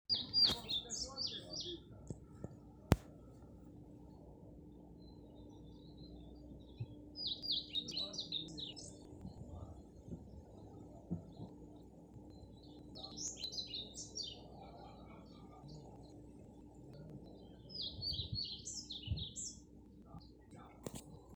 Pied Flycatcher, Ficedula hypoleuca
Ziņotāja saglabāts vietas nosaukumsGaujiena
StatusSinging male in breeding season